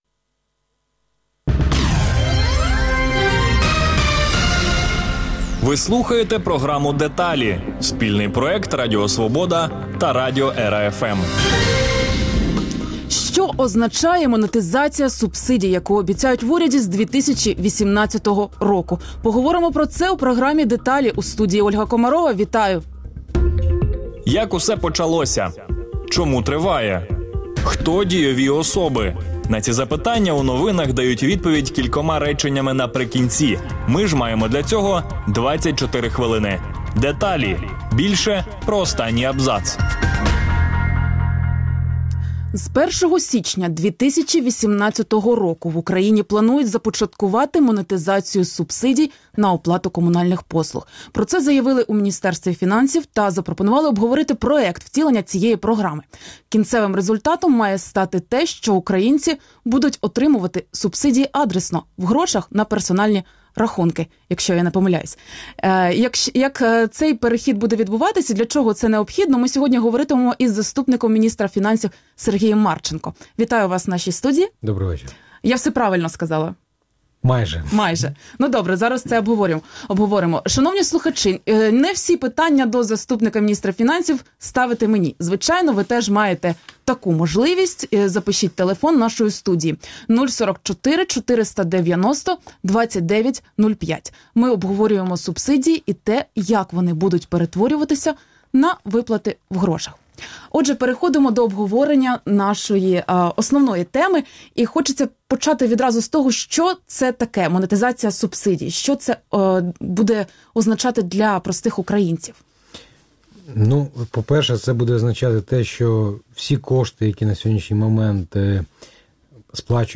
Гість: Сергій Марченко, заступник міністра фінансів України Програма «Деталі» - це відповіді на ваші запитання на тему, почуту в новинах.